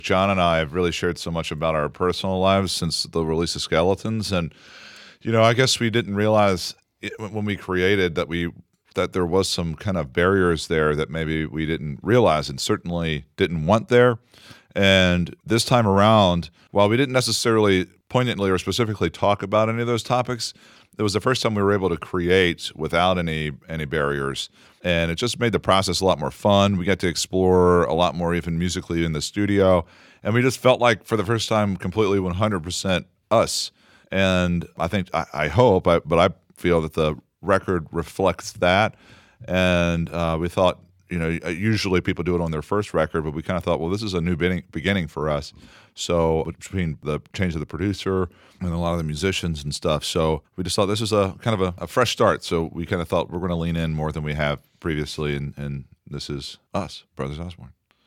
Audio / Brothers Osborne's TJ Osborne explains why they self-titled their fourth studio album.